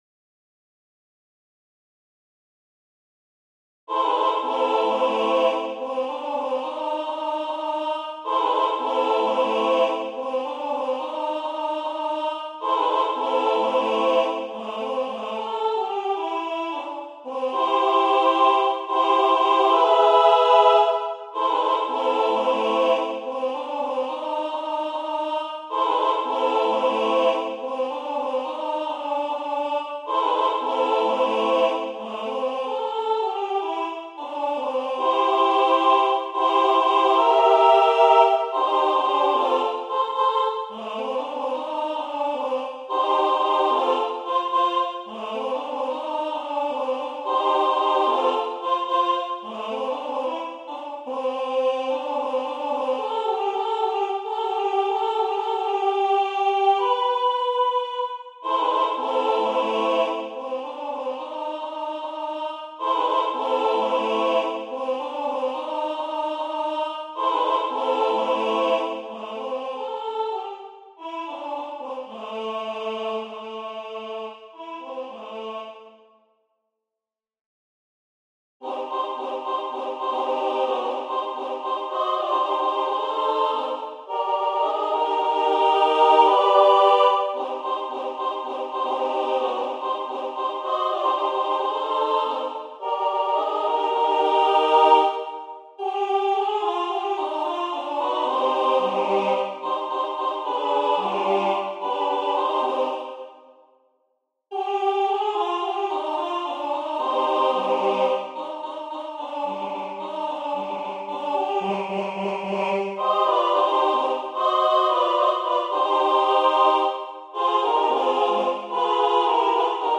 Opracowanie na chór SAT.